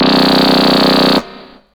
SYNTHBASS4-R.wav